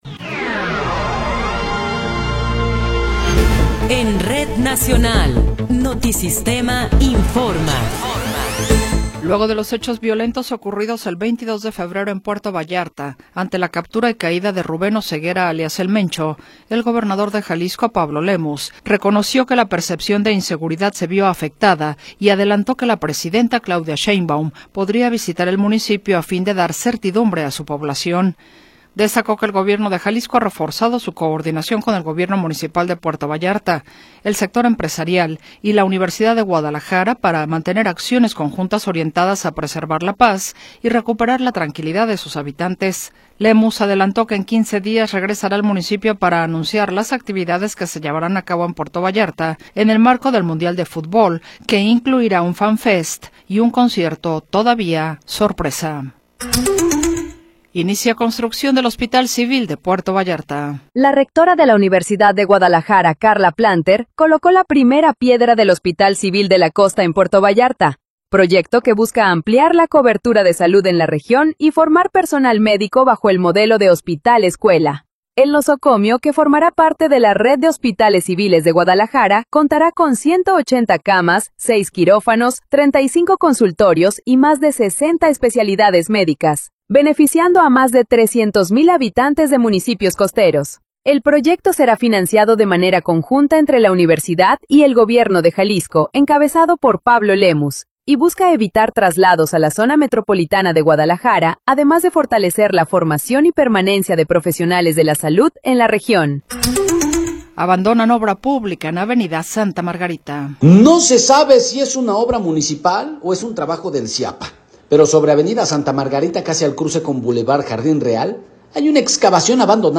Noticiero 17 hrs. – 24 de Abril de 2026